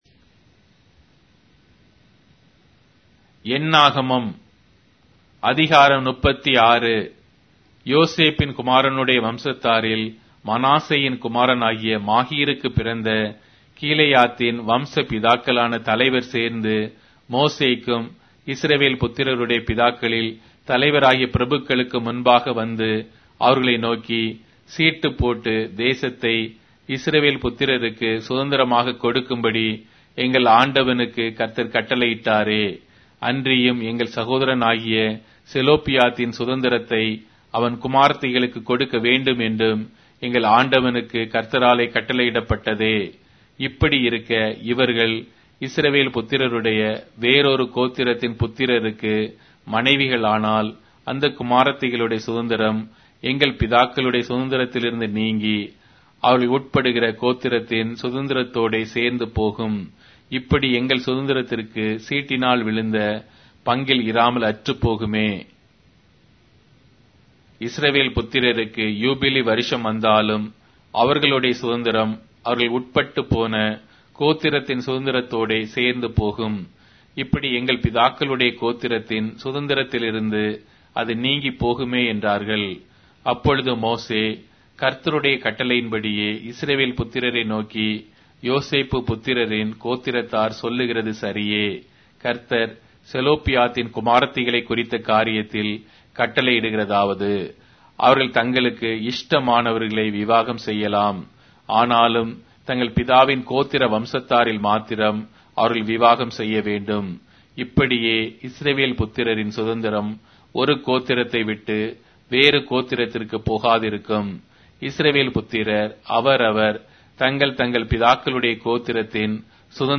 Tamil Audio Bible - Numbers 20 in Irvmr bible version